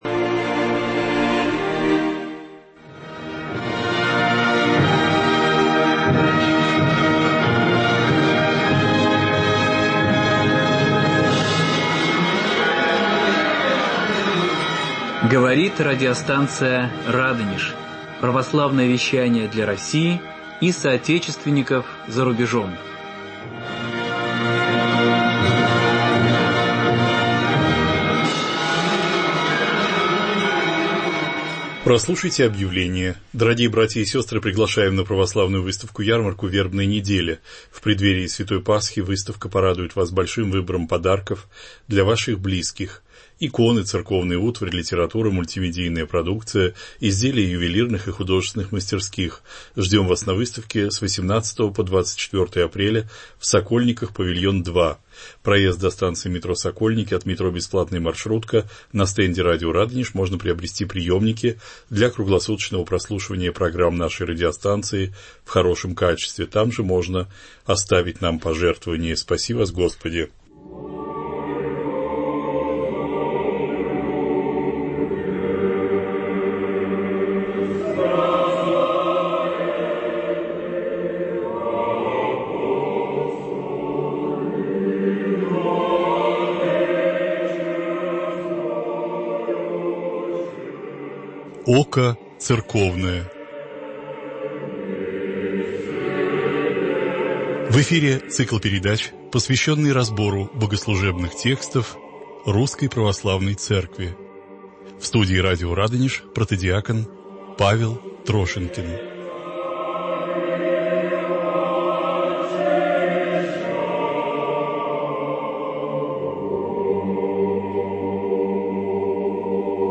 Очень увлекательны и поучительны ваши беседы.